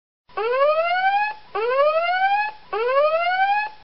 Alarma virus